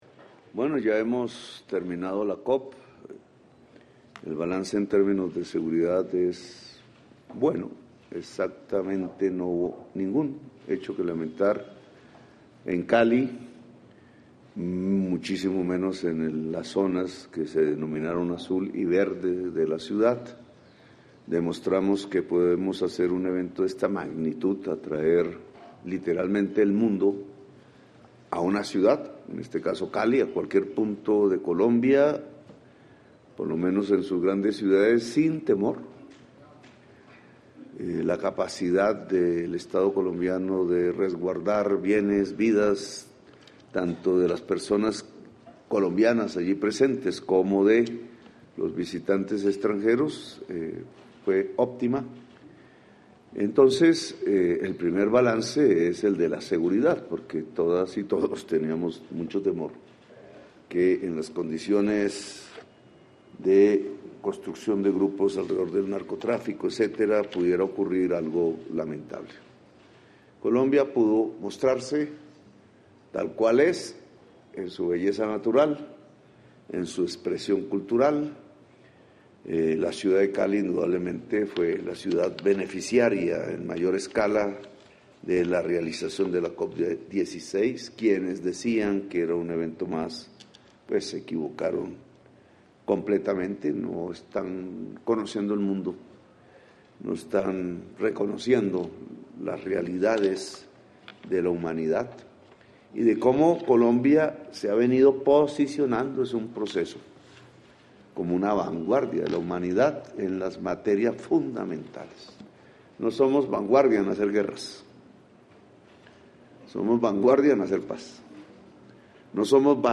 En declaración a medios el mandatario destacó que se movilizaron más de 100 millones de dólares en donaciones para la biodiversidad del país.